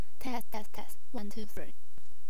我按照你的命令一步一步地走， 麦克风可以工作，但它似乎是不正常的。
我录制的声音已被加载，听起来好像 有些声音数据丢失了。